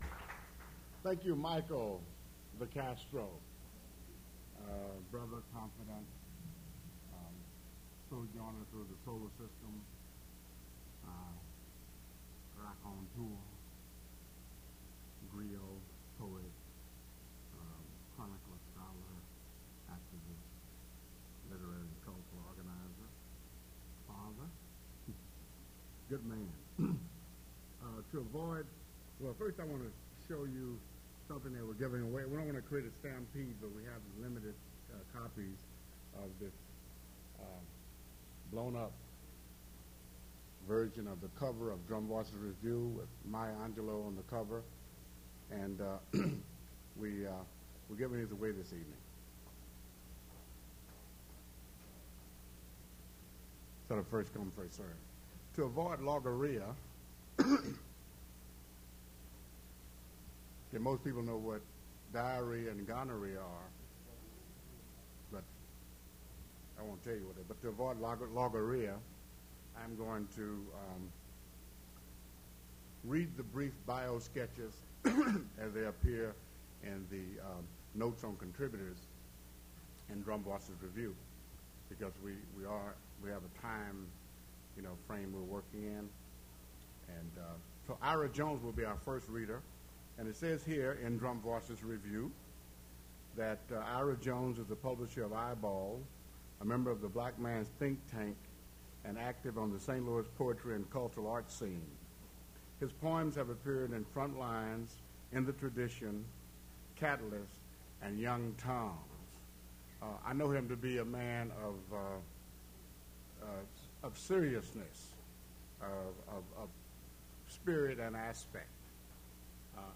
poetry reading at Duff's Restaurant
mp3 edited access file was created from unedited access file which was sourced from preservation WAV file that was generated from original audio cassette. Language English Identifier CASS.759 Series River Styx at Duff's River Styx Archive (MSS127), 1973-2001 Note the volume is very faint; there are several long pauses in the first five minutes.